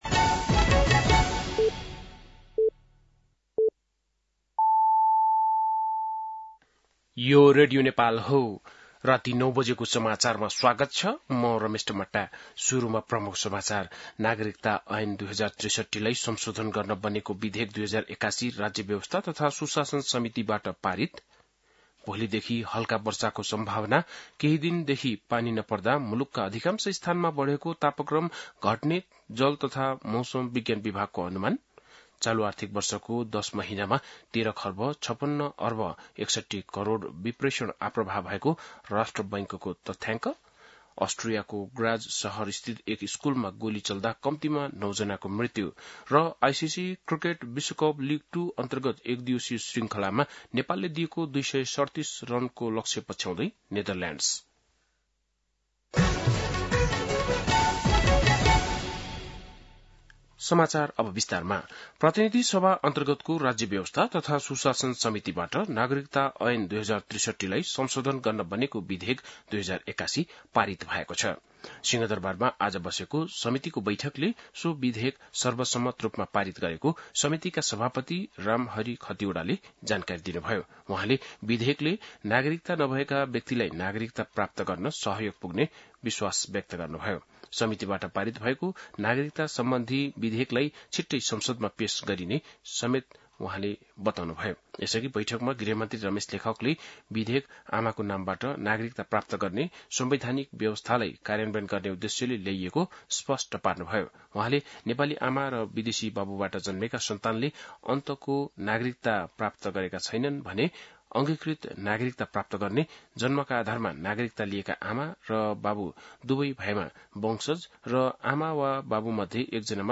बेलुकी ९ बजेको नेपाली समाचार : २७ जेठ , २०८२
9-pm-news-2-27.mp3